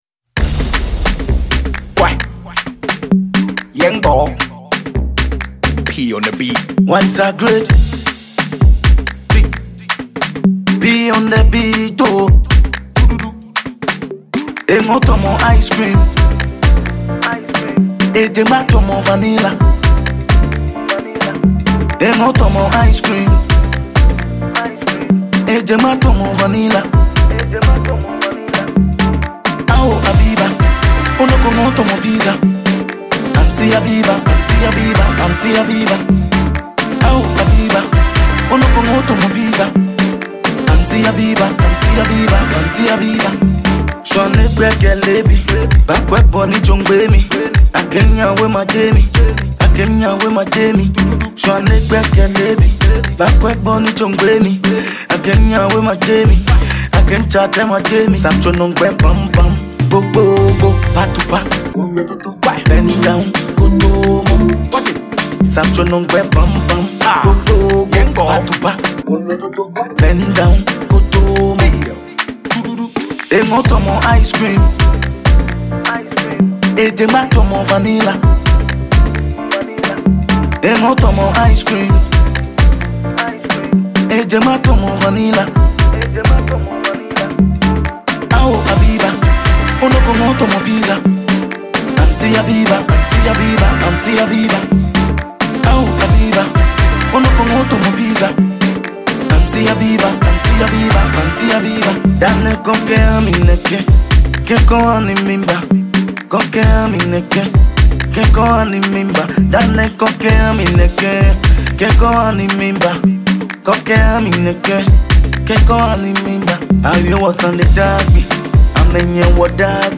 Ghanaian Afrobeat sensation
playful and catchy new single